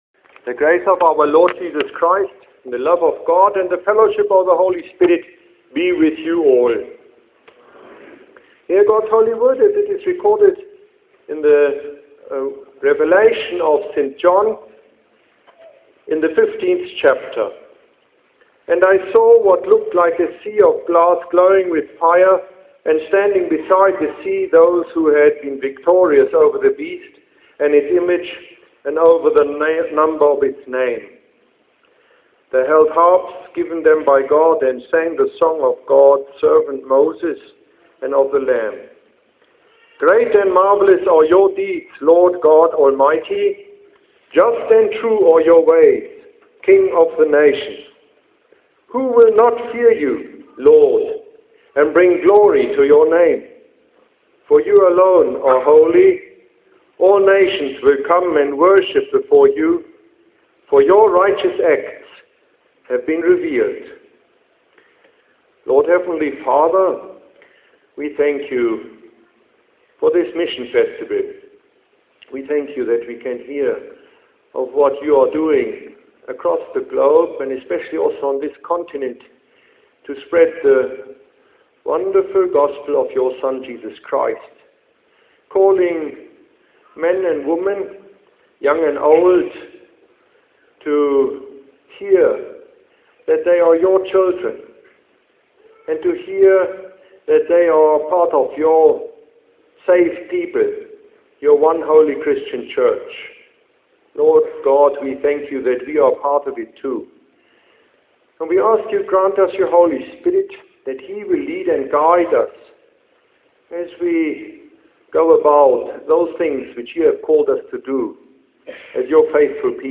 Today Lutheran Churches celebrated the mission of the triune God in the Aula of the University of Pretoria.